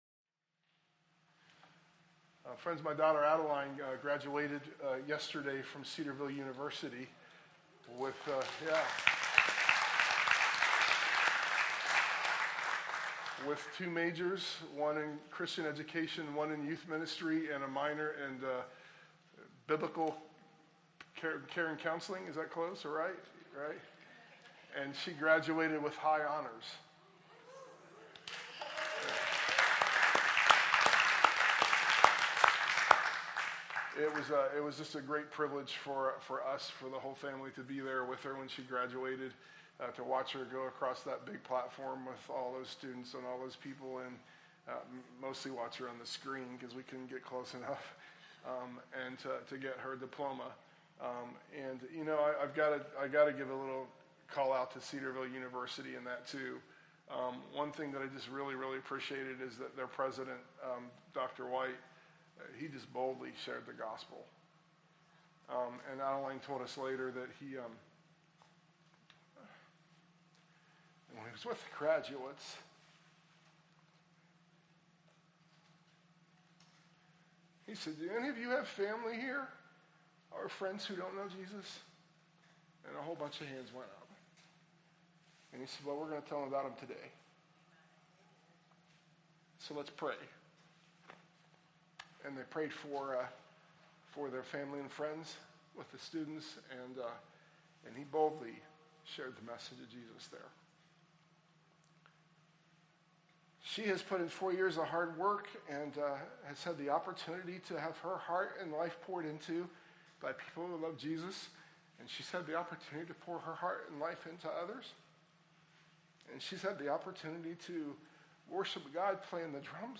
sermon_audio_mixdown_5_5_24.mp3